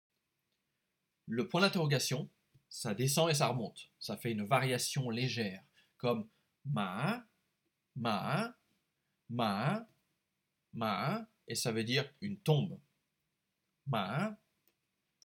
Cours sur la prononciation
Dấu hỏi